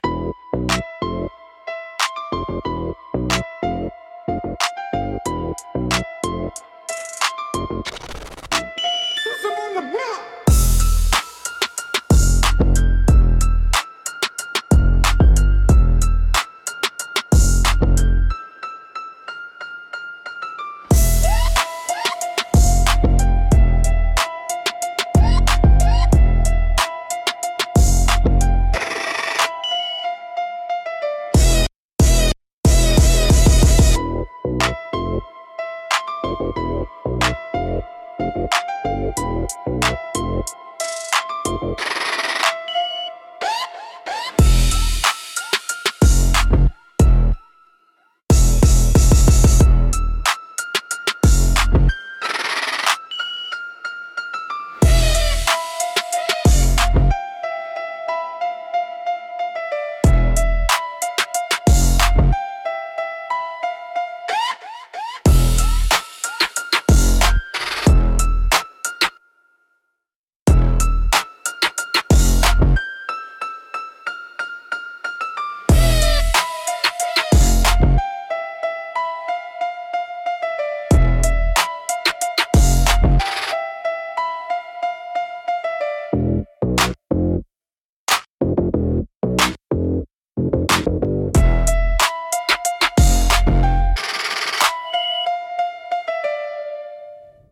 Instrumentals - No Permission, Just Presence (1)